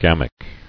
[gam·ic]